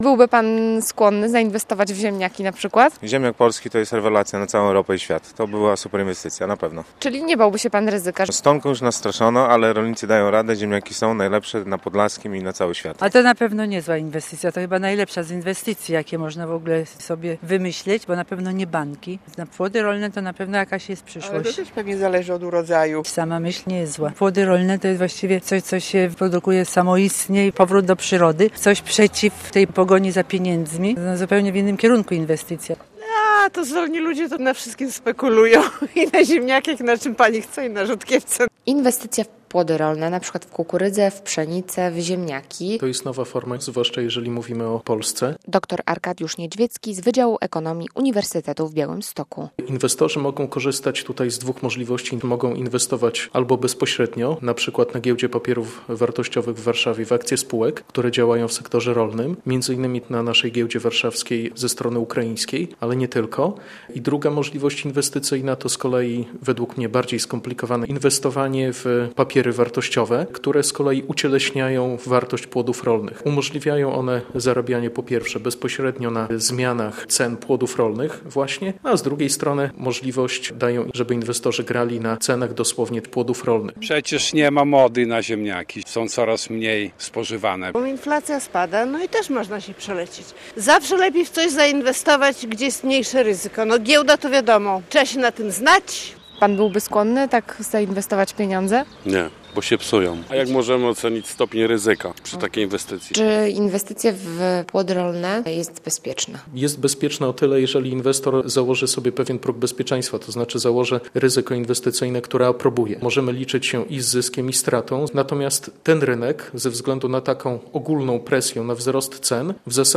Inwestycje - relacja